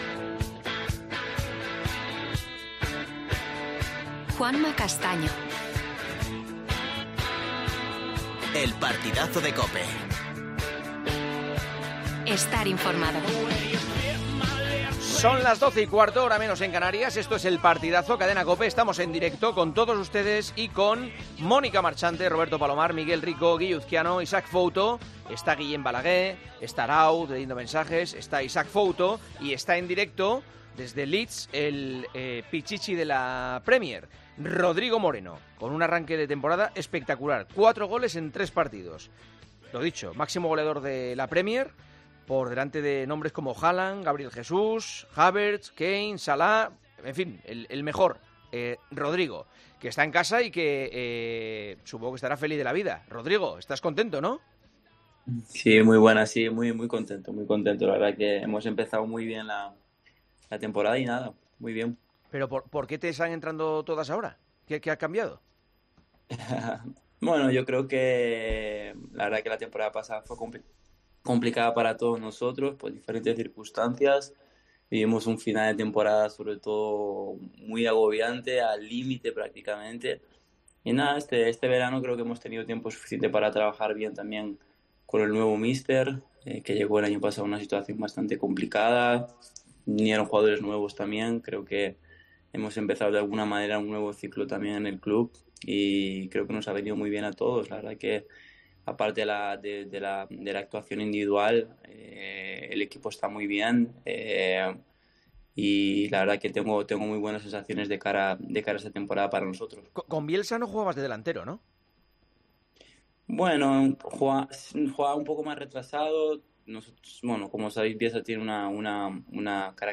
Juanma Castaño entrevista al actual pichichi de la Premier League, Rodrigo Moreno, delantero del Leeds, que lleva cuatro goles en tres jornadas.